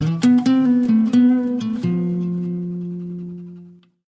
Catégorie: Messages - SMS